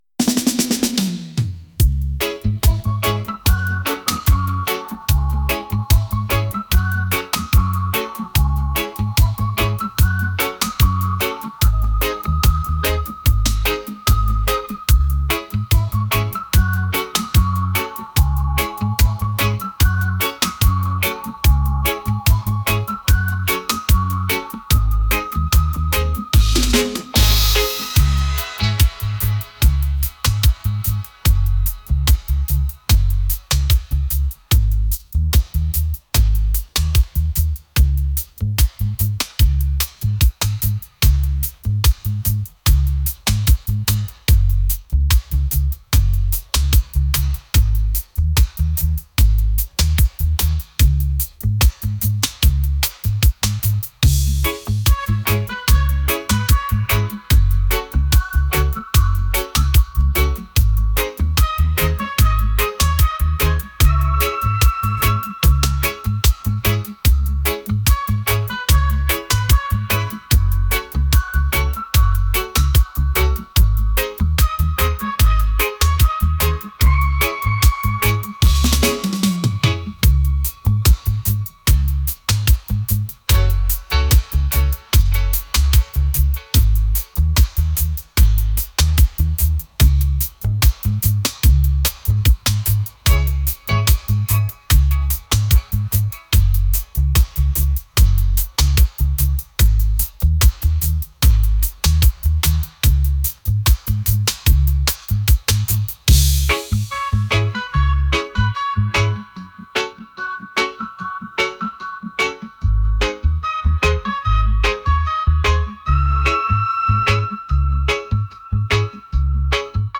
reggae | soul & rnb | lounge